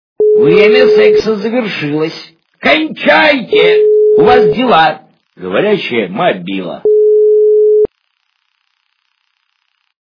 При прослушивании Говорящая мобила - Кончайте у Вас дела! качество понижено и присутствуют гудки.